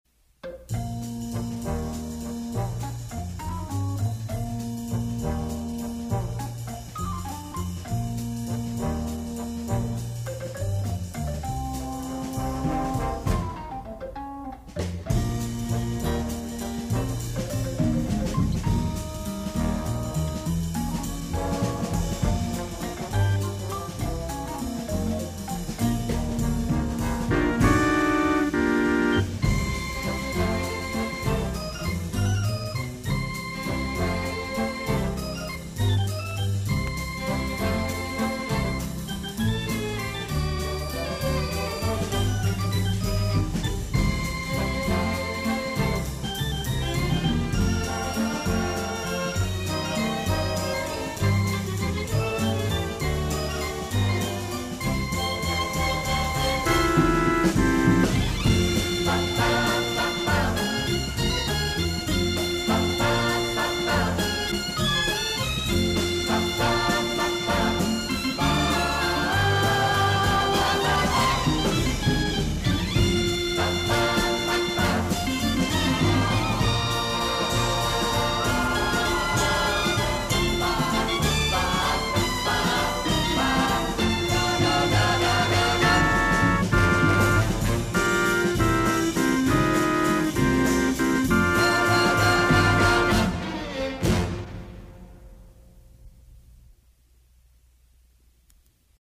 het is een wals